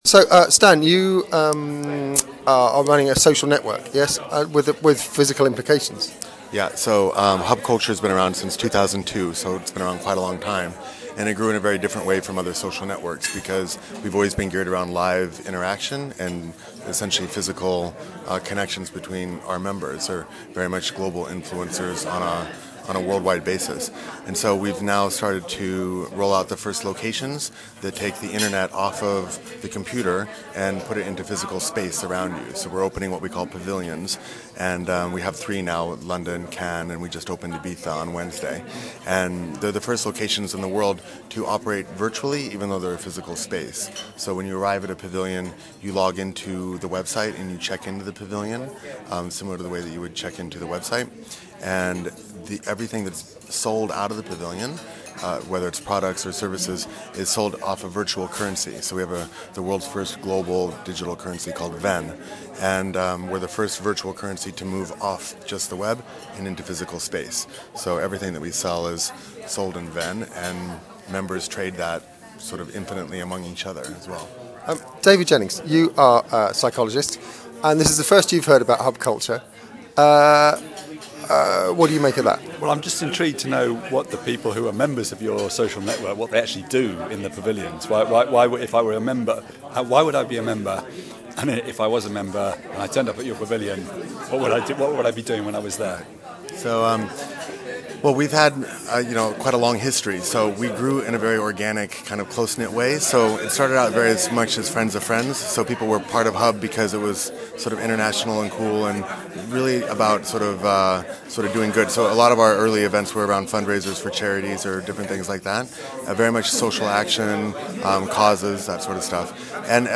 The Amplified team are today at Reboot Britain - a one day conference looking at almost every area of civic life in the UK - education, politics, the economy, the environment, public services - and how emerging technologies can resource, affect and change the way we live.
a 2-part conversation